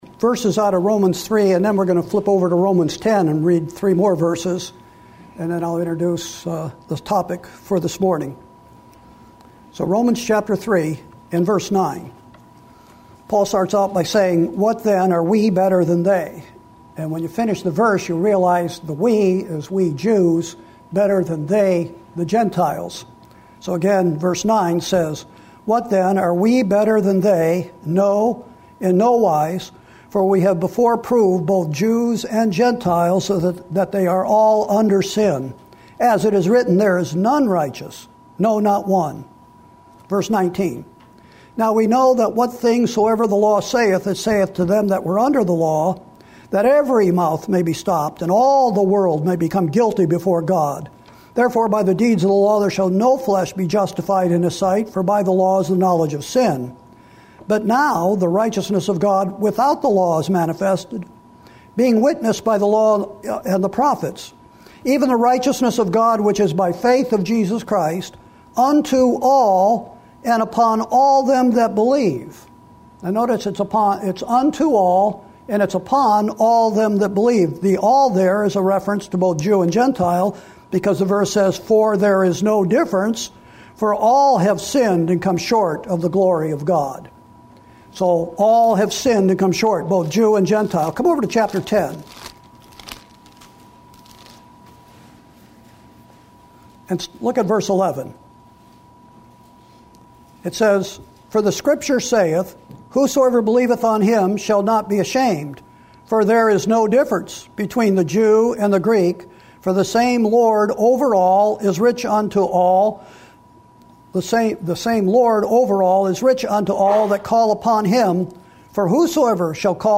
Sermons & Single Studies / Sunday 11am